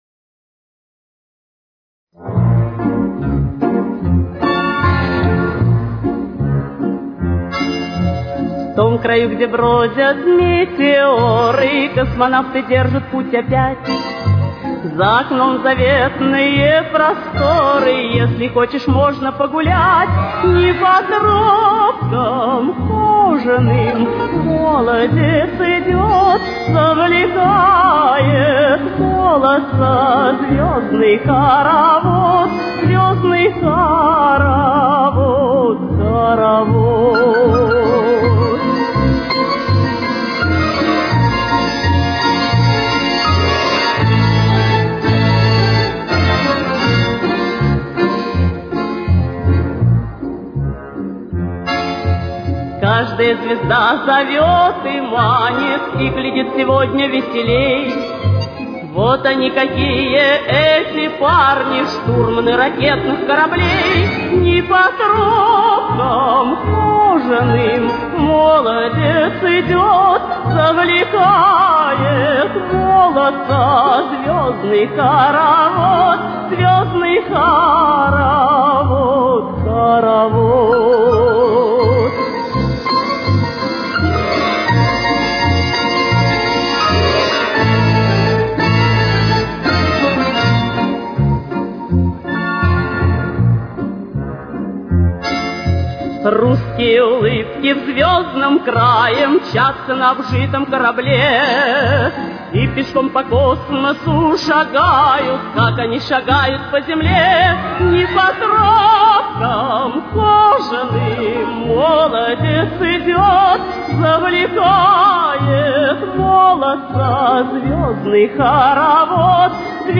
с очень низким качеством (16 – 32 кБит/с)
Темп: 64.